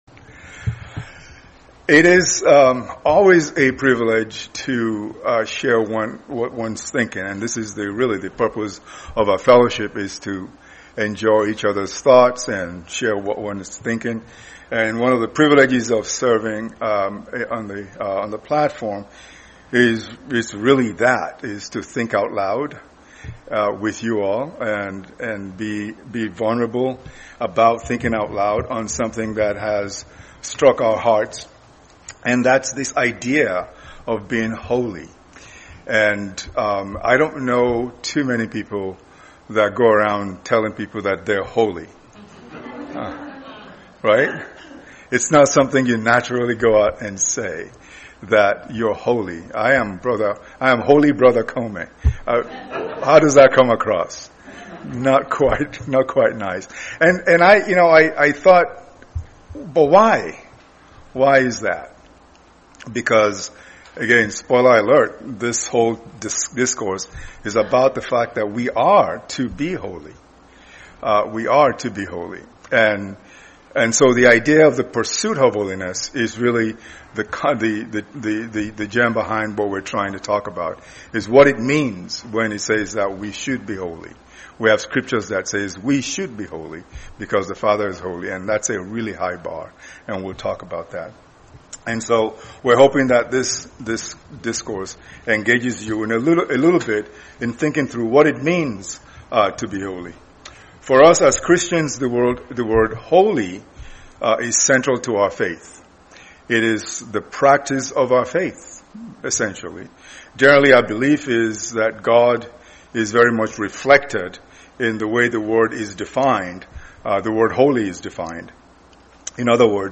Series: 2025 Orlando Convention